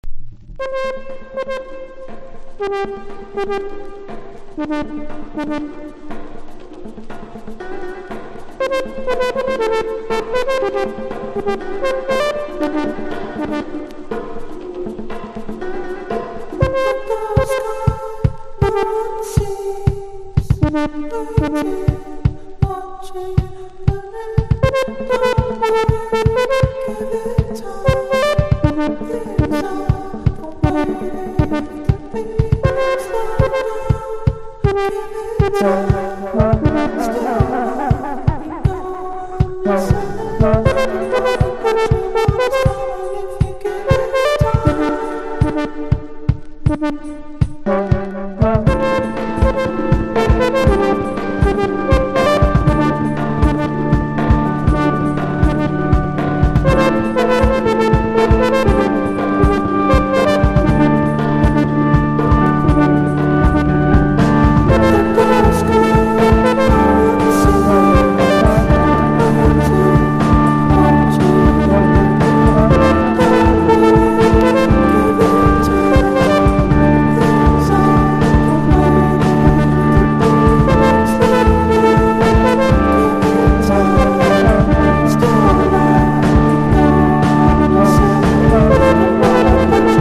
INDIE DANCE
トランペットとシンセによるエレクトロニカなサウンド。